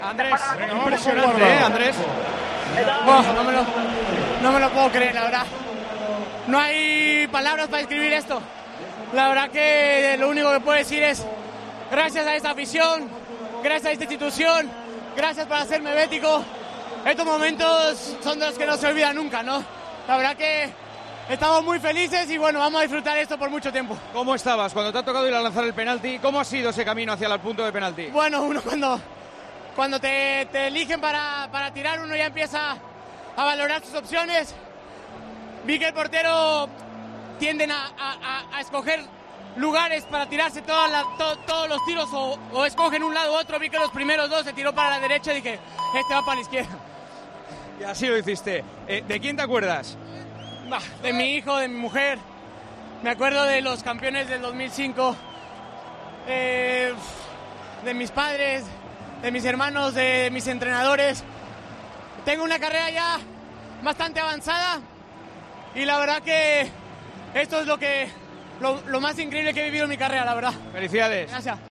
El mexicano ha estado con Juanma Castaño en el césped de La Cartuja tras la final de Copa y ha aprovechado para "dar las gracias a Dios por hacerme del Betis".